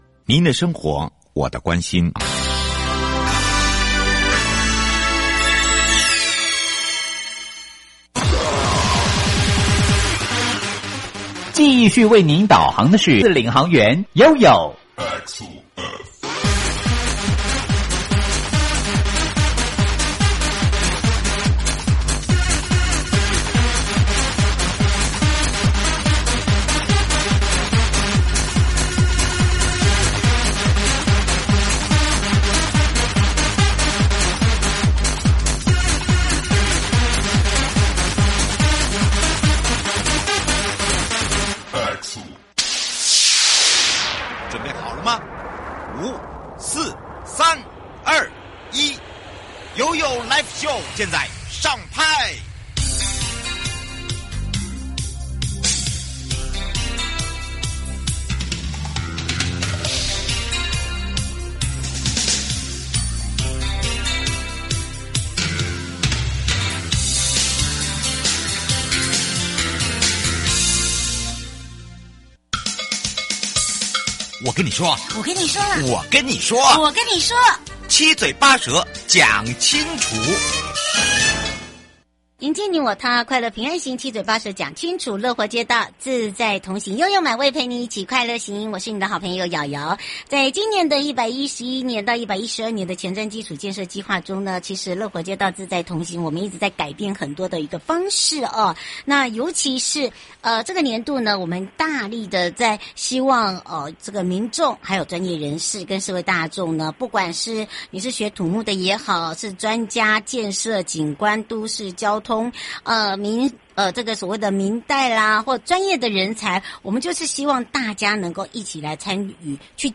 受訪者： 營建你我他 快樂平安行~七嘴八舌講清楚~樂活街道自在同行!(一) 【3/19人本規劃師論壇，人本規劃